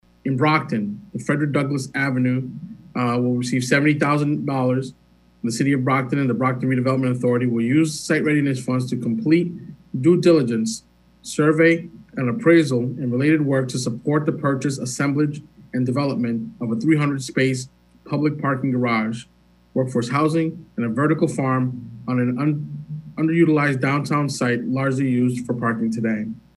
MassDevelopment President & CEO Dan Rivera made the announcement on Tuesday.